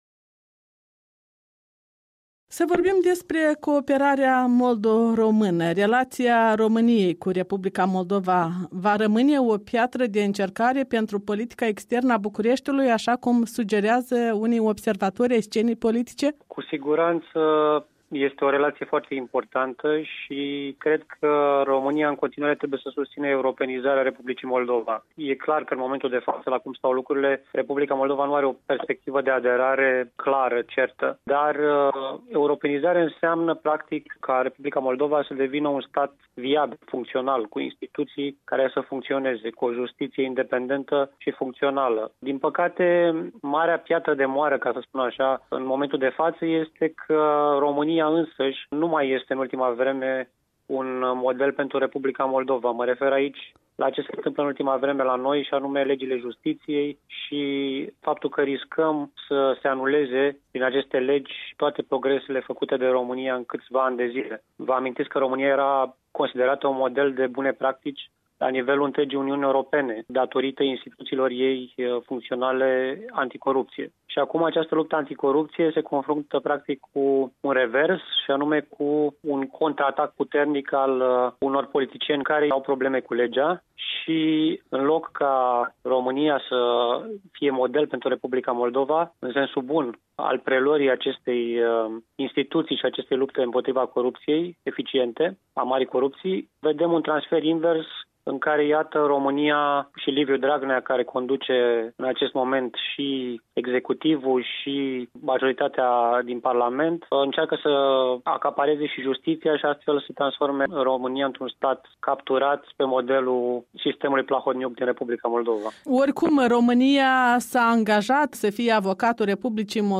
Un interviu cu vicepreședintele comisiei din Camera Deputaților pentru românii din afara granițelor.
Un interviu cu Matei Adrian Dobrovie